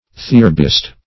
theorbist - definition of theorbist - synonyms, pronunciation, spelling from Free Dictionary Search Result for " theorbist" : The Collaborative International Dictionary of English v.0.48: Theorbist \The*or"bist\, n. (Mus.) One who plays on a theorbo.